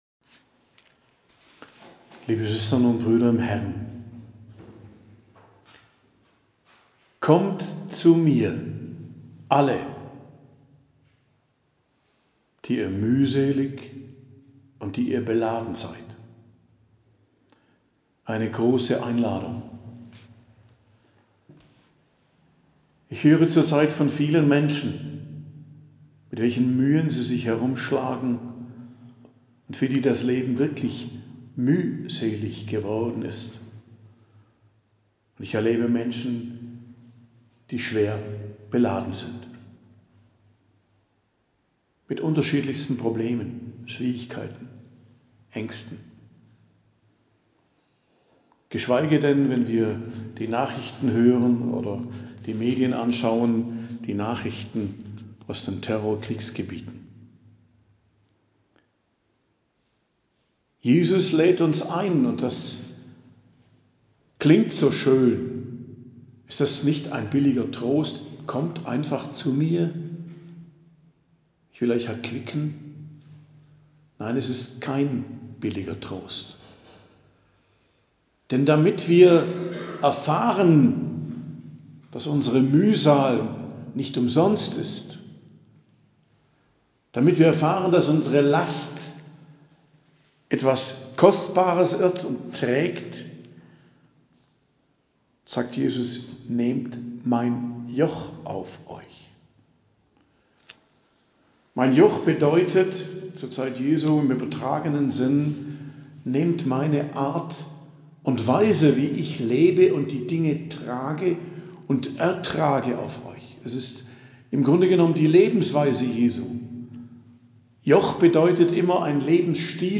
Predigt am Mittwoch der 2. Woche im Advent, 13.12.2023